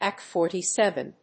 /ˌeɪkeɪfɔːtiˈsevən(米国英語)/